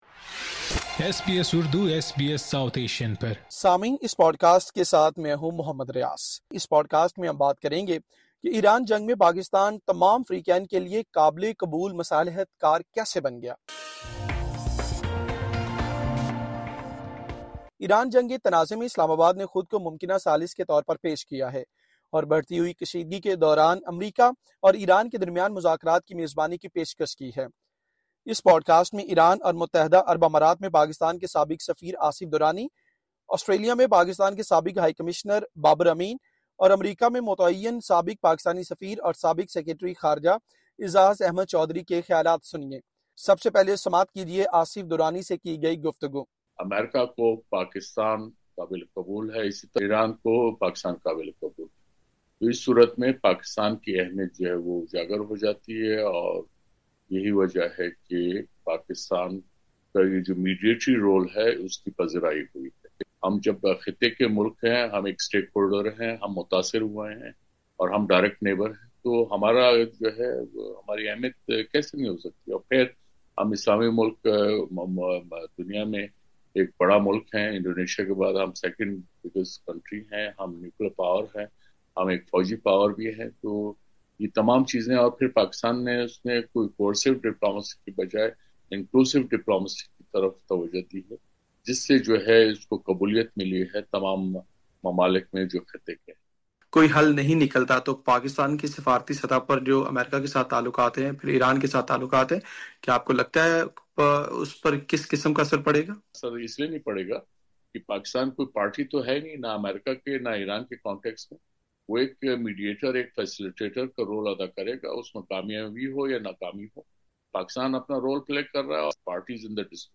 Islamabad has positioned itself as a potential mediator in the Iran conflict, offering to host talks between the United States and Iran amid rising tensions. Hear the perspectives of Asif Durrani, former Ambassador of Pakistan to Iran and the UAE; Babar Amin, former Pakistani Ambassador to Australia; and Aizaz Ahmad Chaudhry, former Pakistani Ambassador to the United States and former Foreign Secretary.